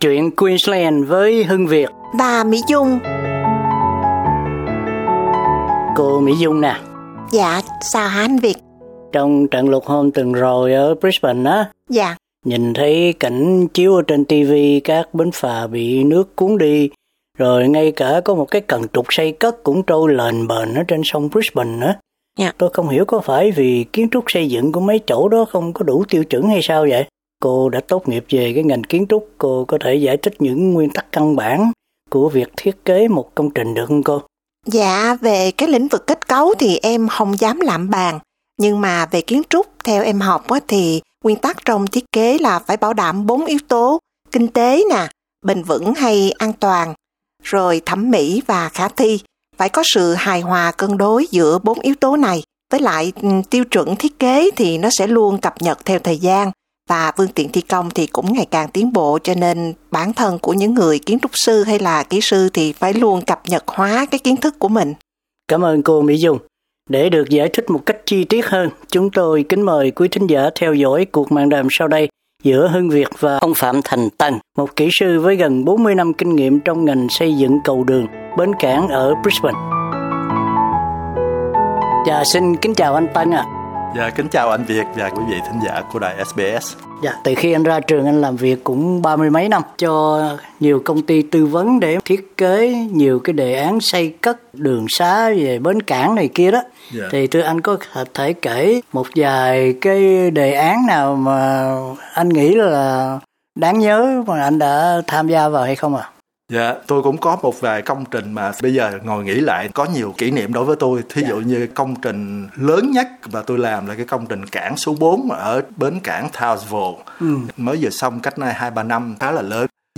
Mạn đàm